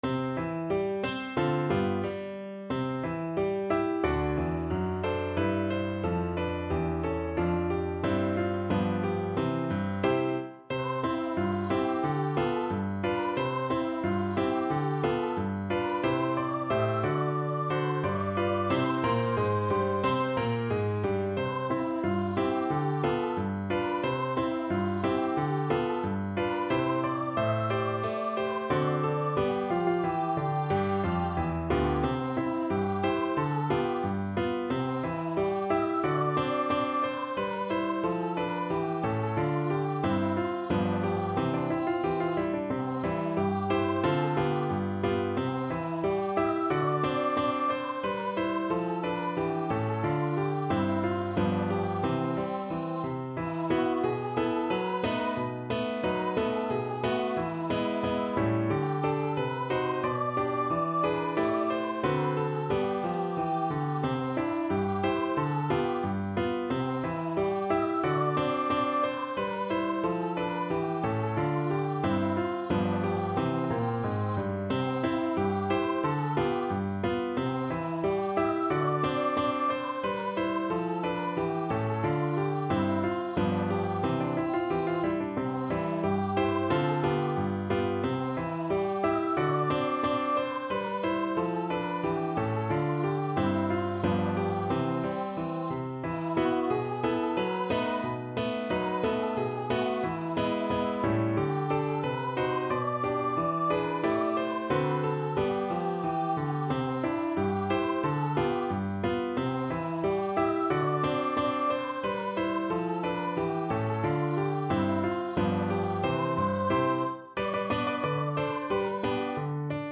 =90 Fast and cheerful
2/2 (View more 2/2 Music)
Pop (View more Pop Voice Music)